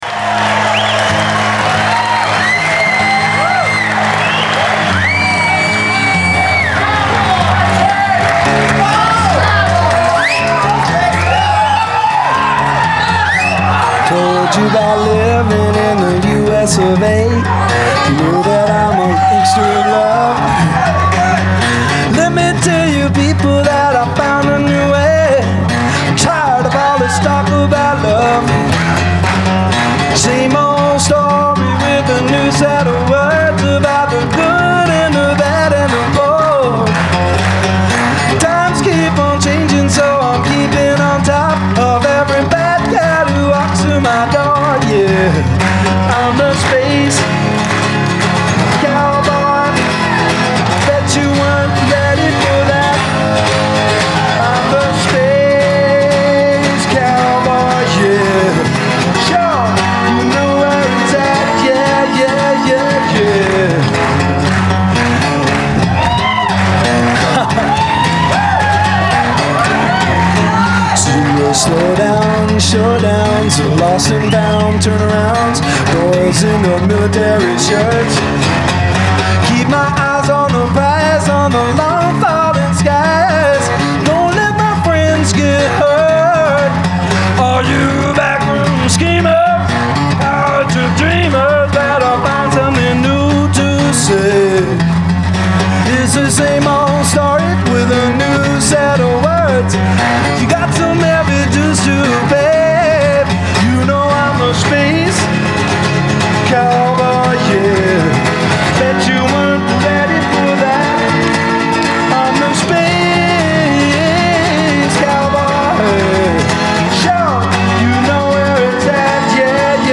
Genre : Rock
Live - Berkeley, CA, July 14, 1972